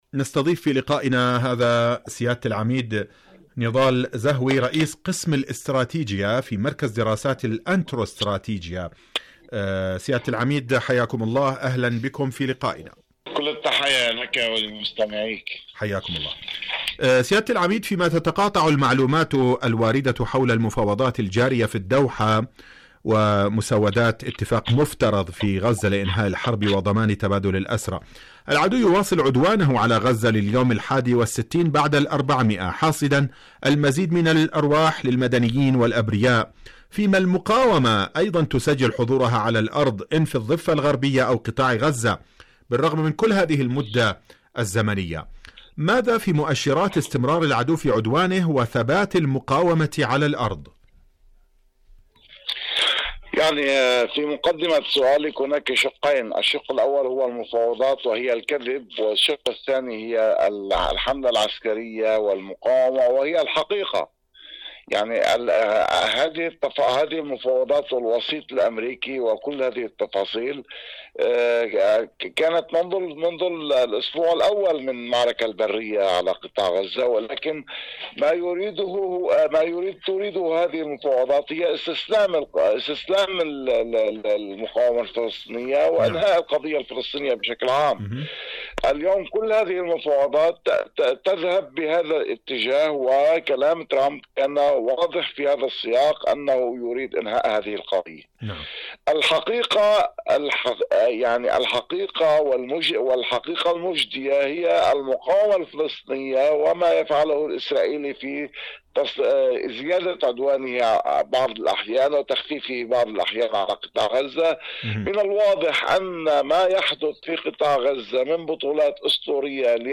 مقابلات برامج إذاعة طهران العربية برنامج فلسطين اليوم مقابلات إذاعية القدس الشريف كيان الاحتلال المسجد الاقصى مابين الضفة والقطاع ومحاور الإسناد الكيان إلى أين؟